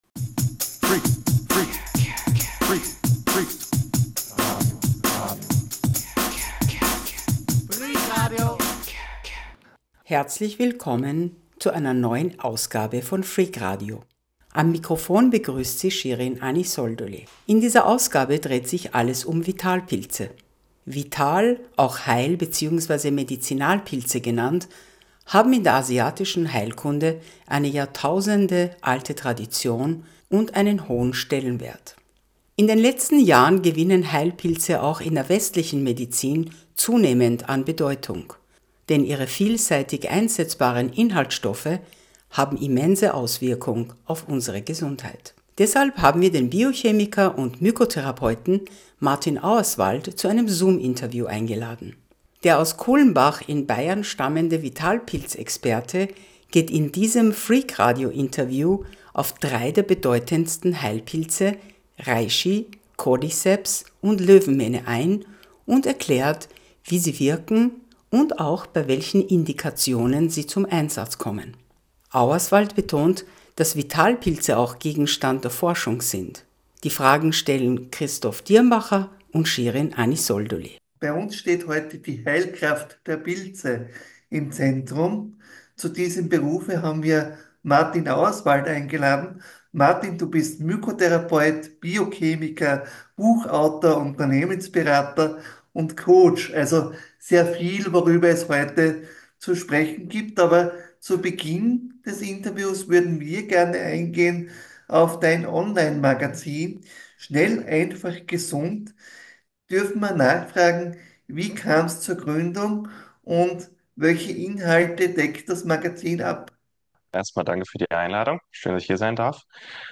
Sie hören ein Interview